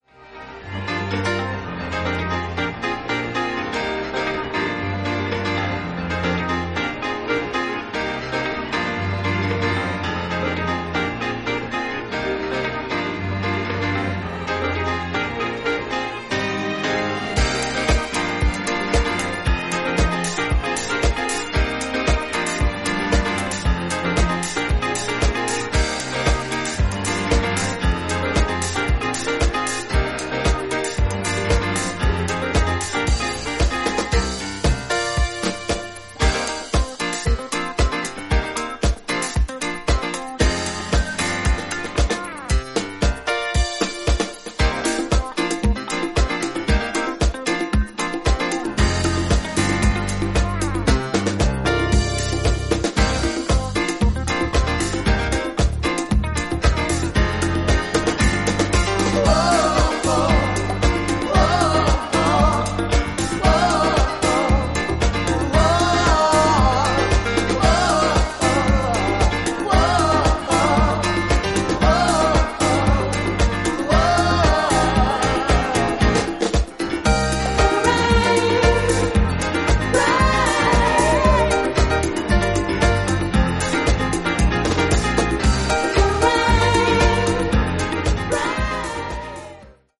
よりエモーショナルでDJユースに仕上げた1枚。